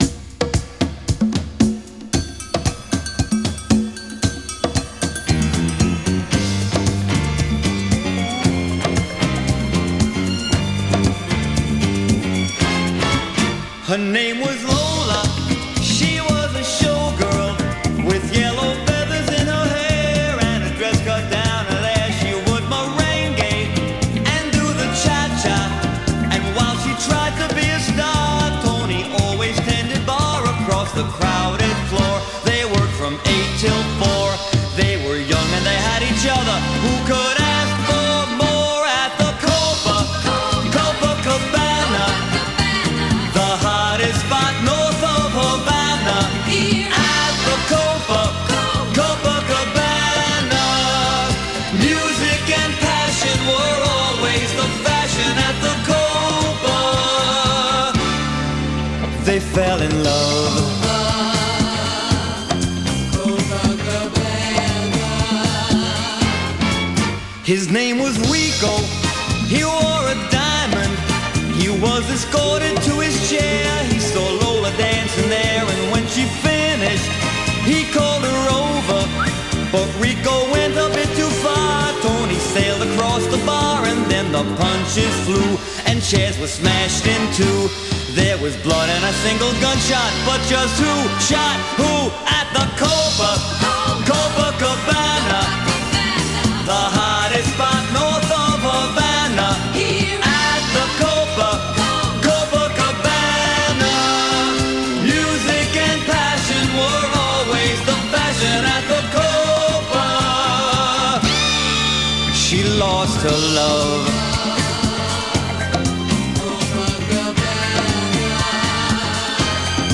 BPM114-119